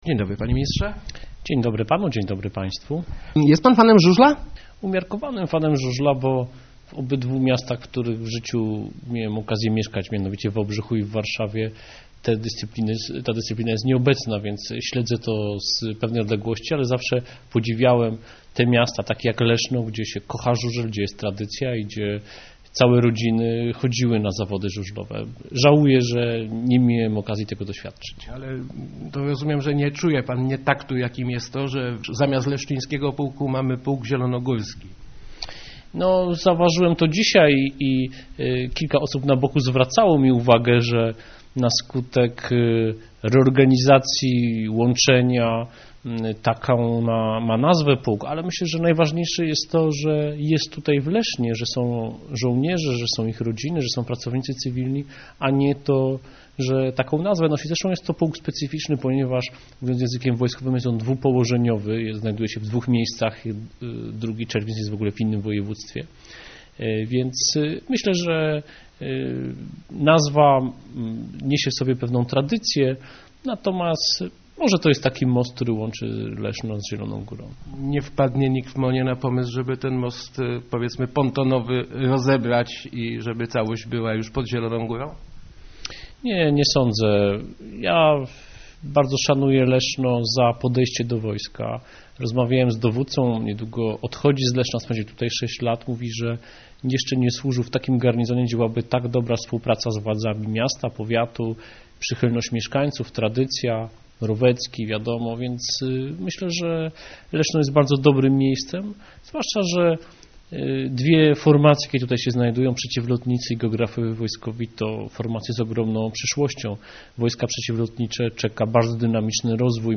Szef MON w Radiu Elka